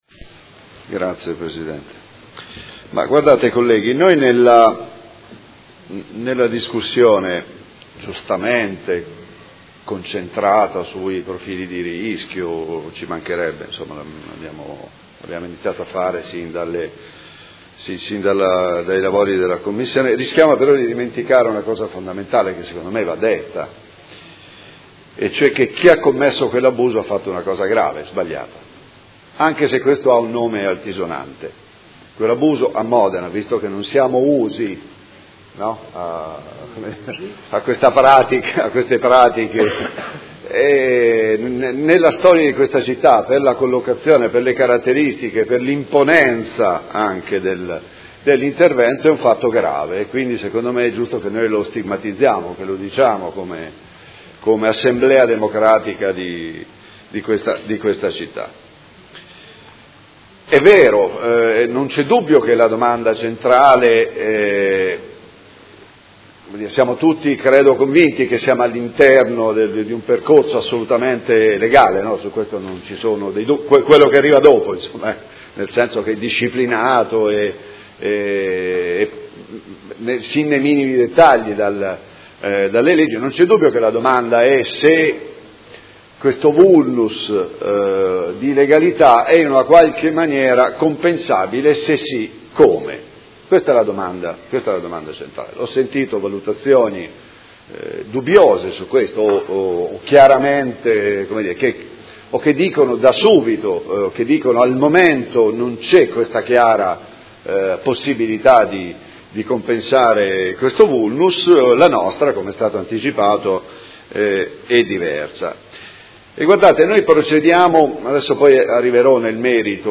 Seduta del 20/12/2018.
Audio Consiglio Comunale